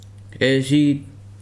[dɛ́ʒíb] n. fire